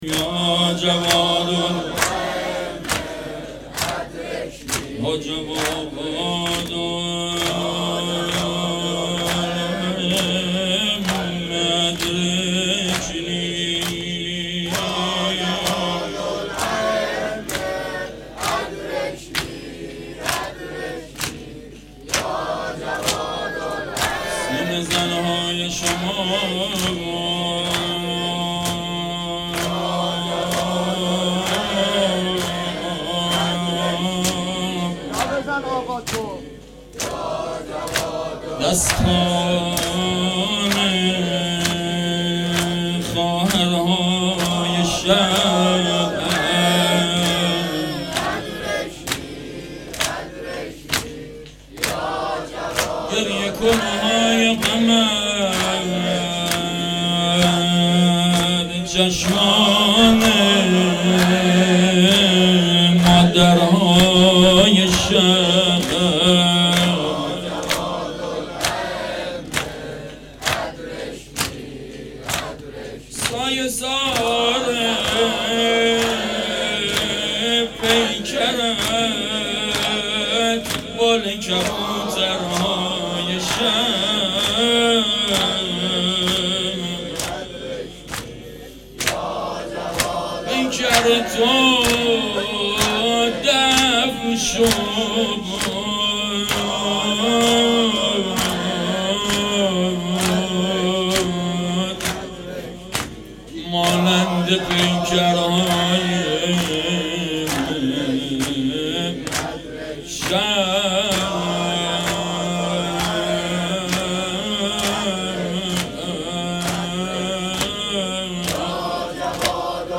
سینه زن های شما _ شعر خوانی
شهادت امام جواد علیه السلام